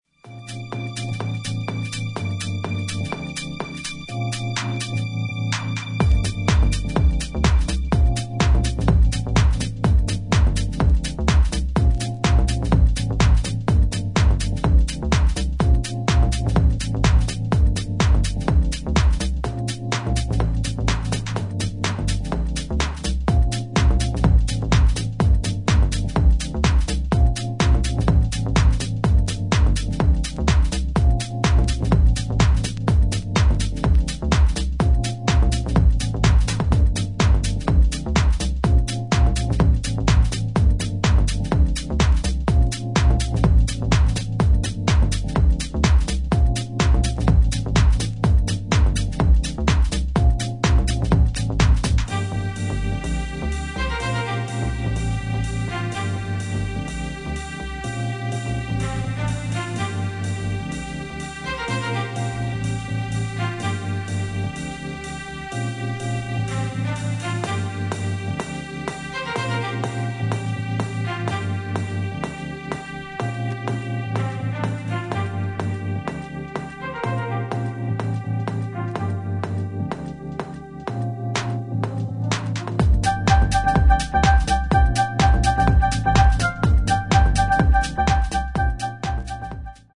ジャジーな要素をアーティストそれぞれが異なる解釈でフィットさせたディープハウス四曲を収録。